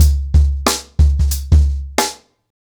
TrackBack-90BPM.55.wav